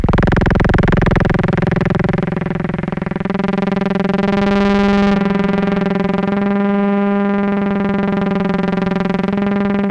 Loudest frequency 512 Hz Recorded with monotron delay and monotron - analogue ribbon synthesizer